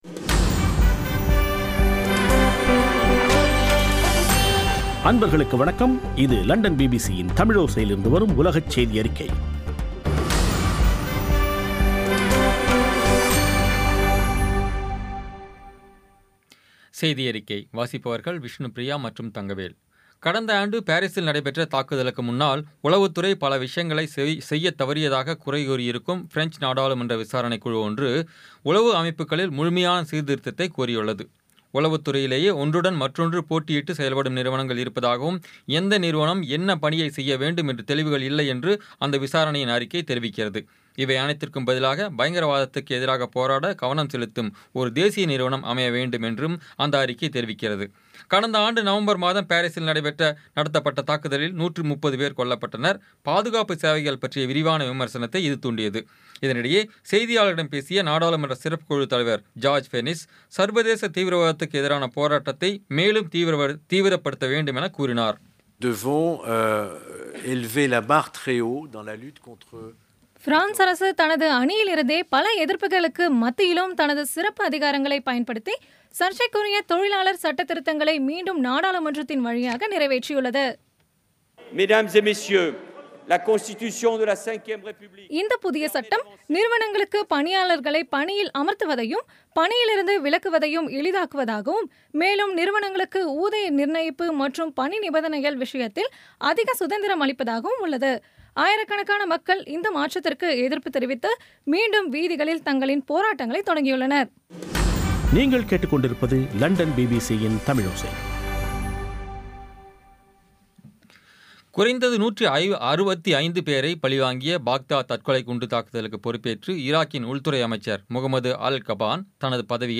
பி பி சி தமிழோசை செய்தியறிக்கை (05/07/2016)